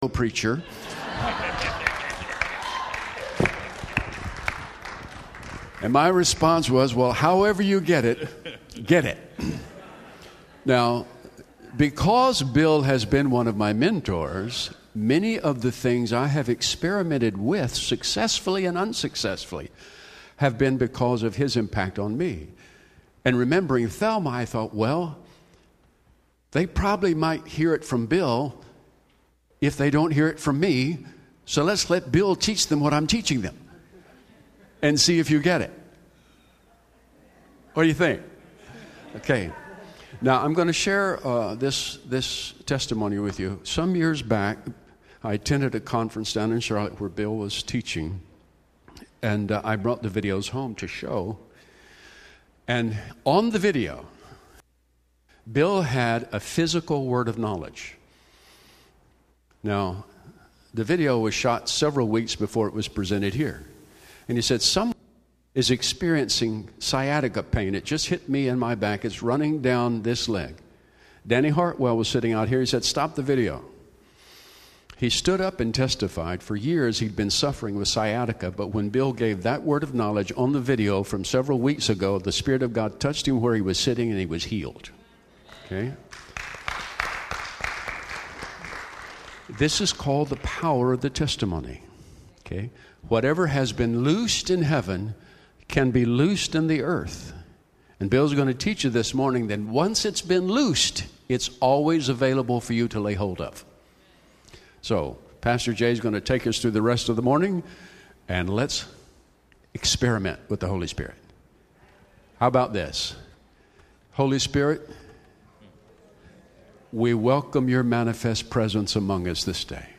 Encounter Service